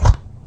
PixelPerfectionCE/assets/minecraft/sounds/mob/sheep/step2.ogg at mc116
step2.ogg